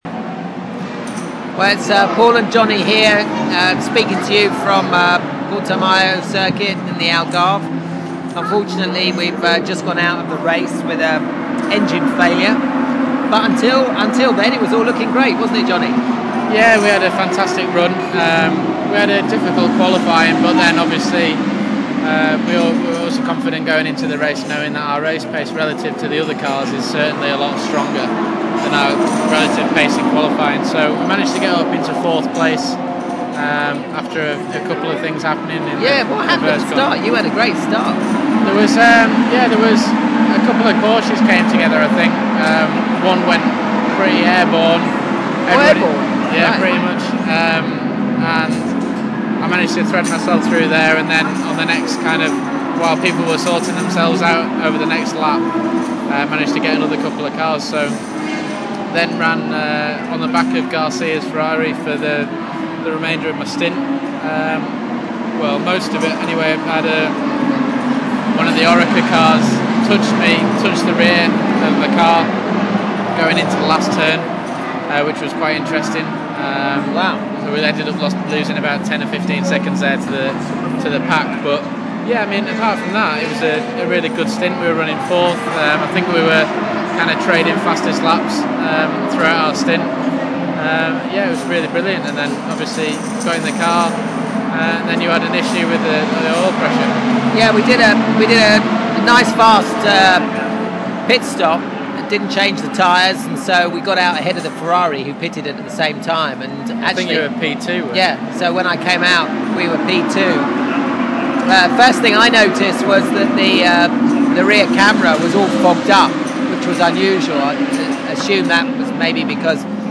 Post Race Chat